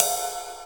Original creative-commons licensed sounds for DJ's and music producers, recorded with high quality studio microphones.
Loudest frequency: 5860Hz Cymbal Sound Clip F Key 06.wav .WAV .MP3 .OGG 0:00 / 0:01 Royality free cymbal sample tuned to the F note. Loudest frequency: 6473Hz Ride Cymbal F Key 08.wav .WAV .MP3 .OGG 0:00 / 0:01 Royality free ride single shot tuned to the F note.
cymbal-sound-clip-f-key-06-vzd.wav